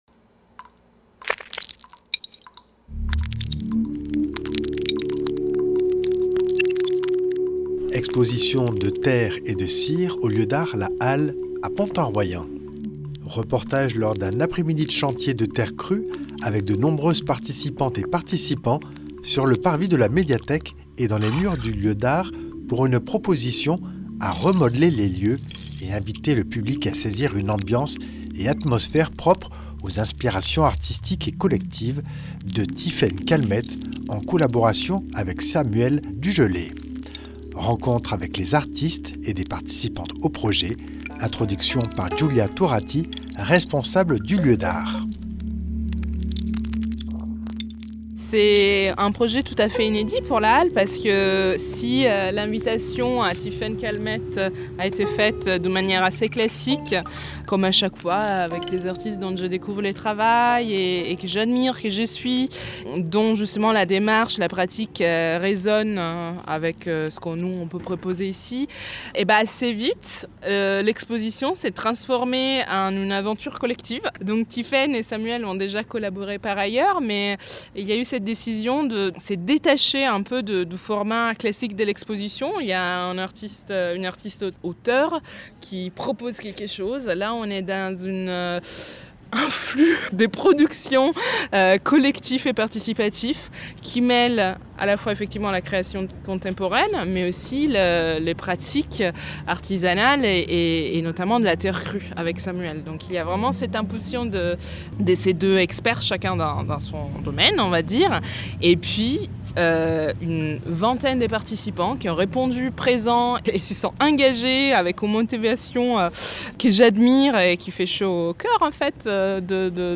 Rencontre avec les artistes et des participantes au projet.